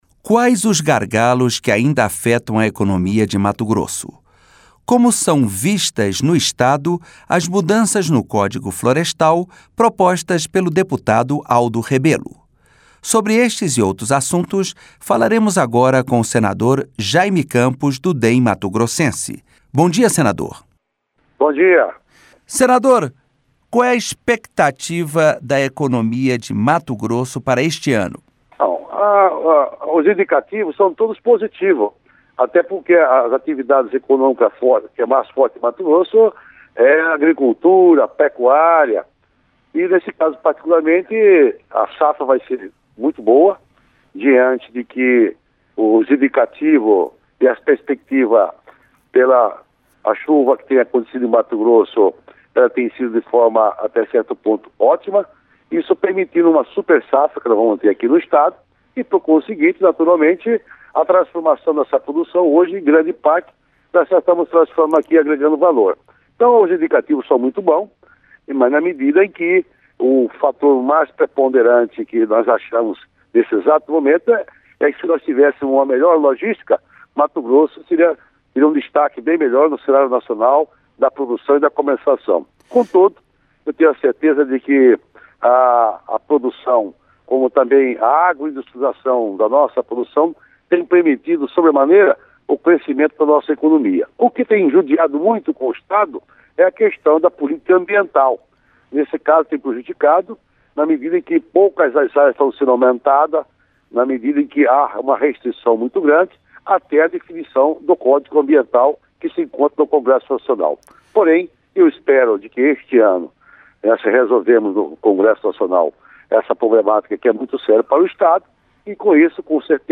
Entrevista com o senador Jayme Campos (DEM-MT).